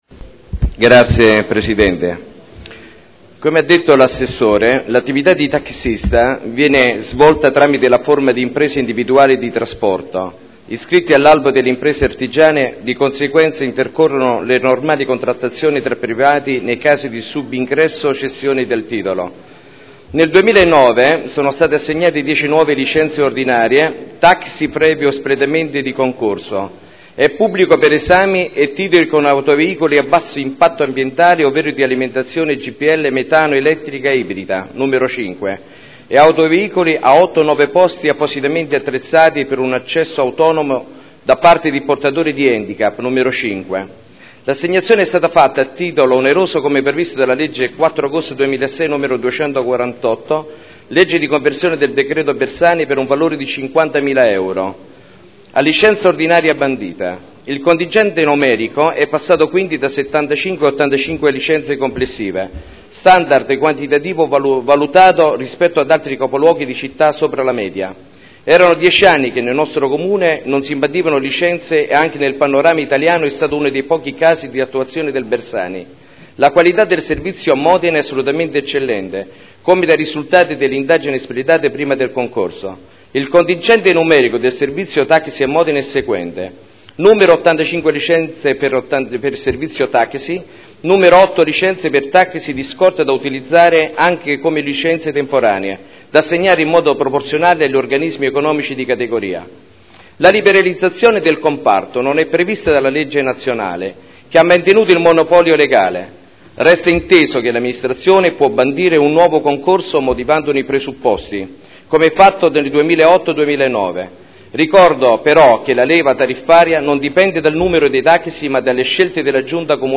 Dibattito su Delibera: Servizi di trasporto pubblico non di linea: taxi e noleggio con conducenti di veicoli fino a 9 posti – Approvazione nuovo Regolamento comunale e modifica art. 28 del Regolamento per l’applicazione della tassa per l’occupazione di spazi ed aree pubbliche e per il rilascio delle concessioni di suolo pubblico (Commissione consiliare del 15 e del 29 marzo 2011)